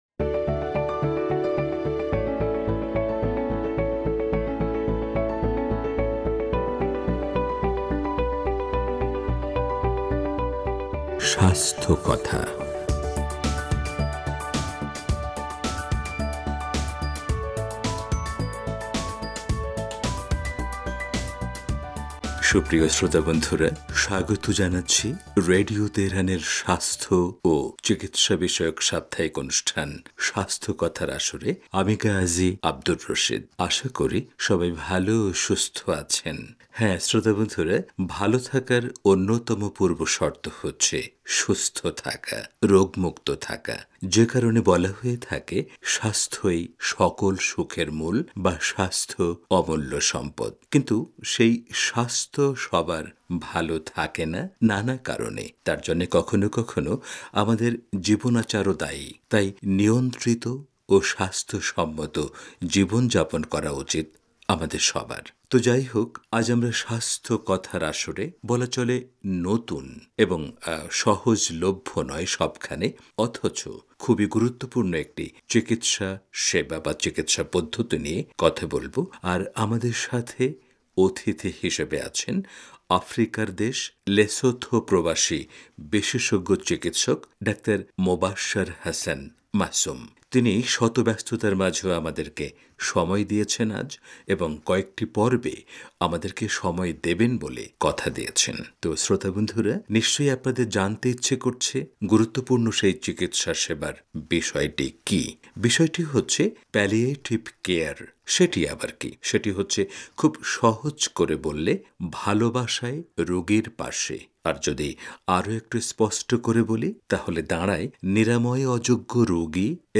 স্বাস্থ্যকথা